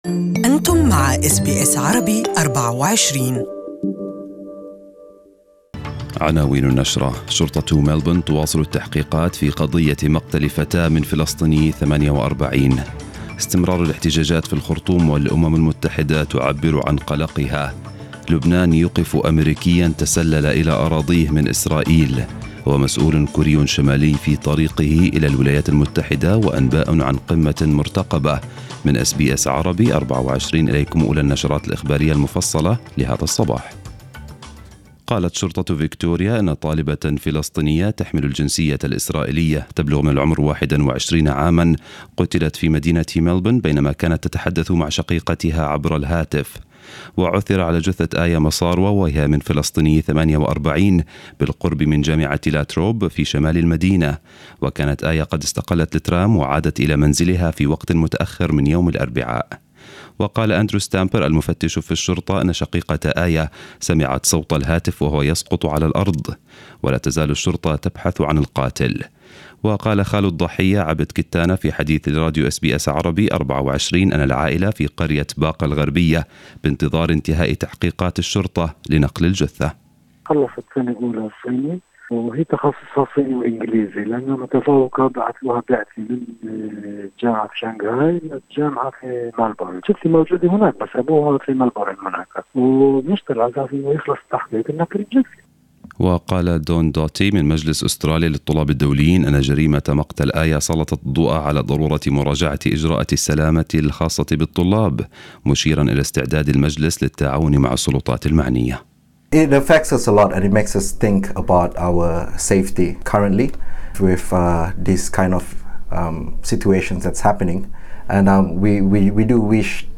News of the morning in Arabic